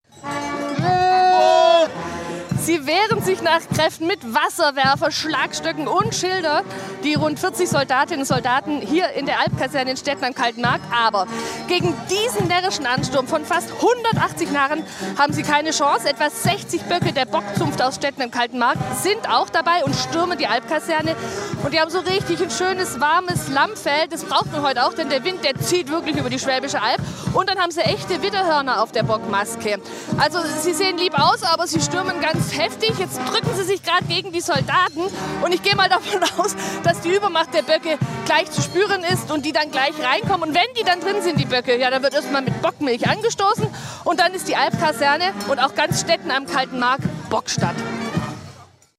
Bockzunft stürmt Albkaserne in Stetten am Kalten Markt
Der Bock trägt um den Hals eine alpine Glocke, deren Klang das charakteristische "Gschell" bildet.
Vor der Albkaserne geht's wild zu: Die Bockzunft versucht die Linie der Soldatinnen und Soldaten zu durchbrechen, um die Kaserne einznehmen.